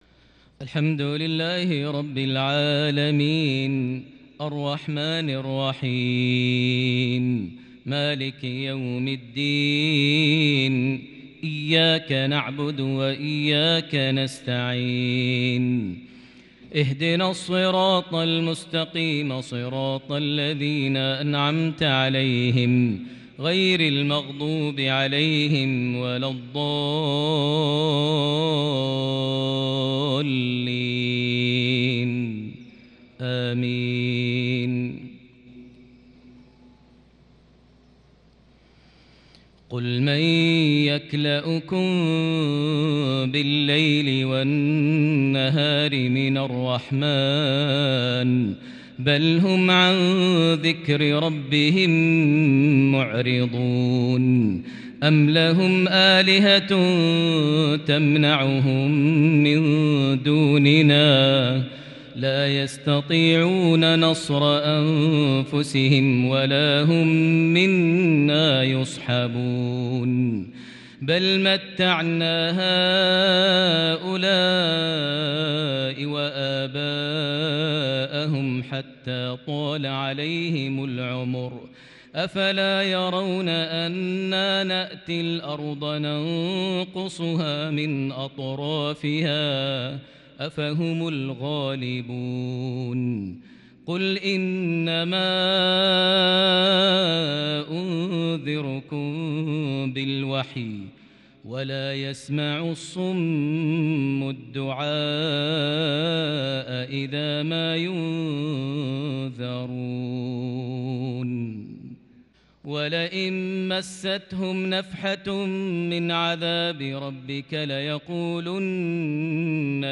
مغربية فذه بالكرد من سورة الأنبياء (42-50) | 19 جمادى الآخر 1442هـ > 1442 هـ > الفروض - تلاوات ماهر المعيقلي